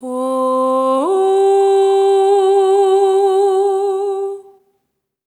SOP5TH C4 -R.wav